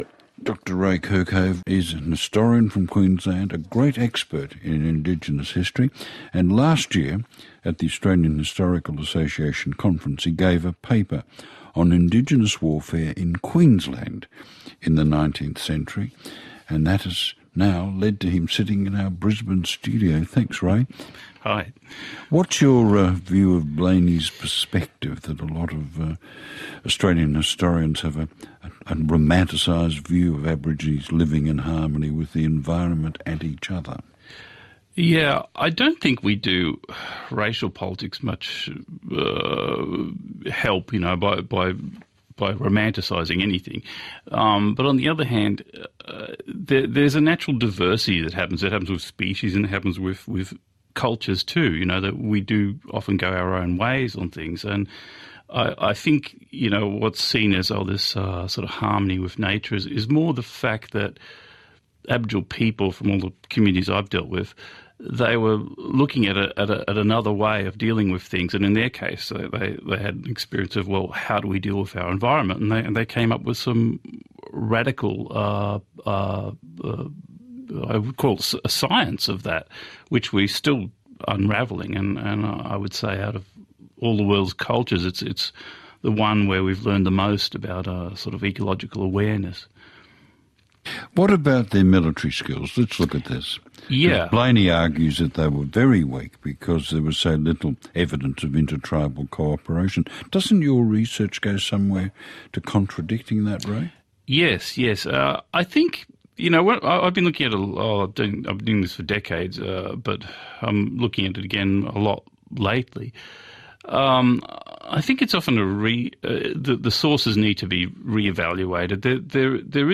Phillip Adams interview